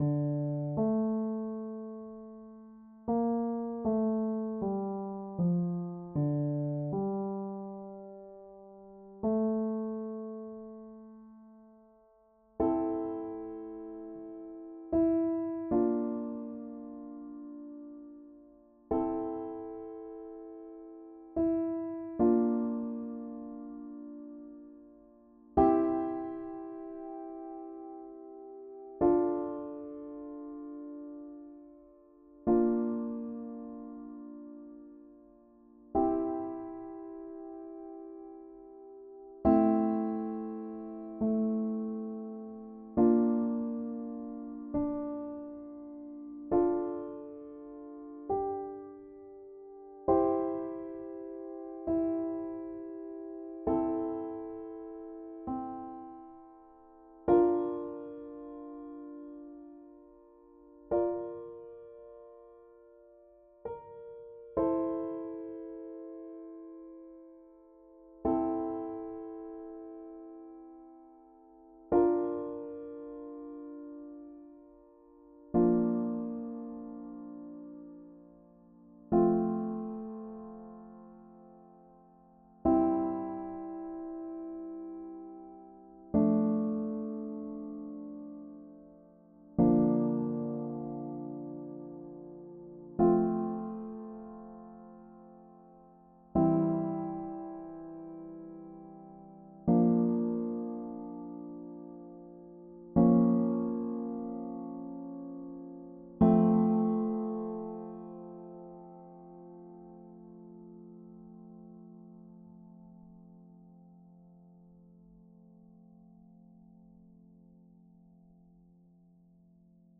Solo, Piano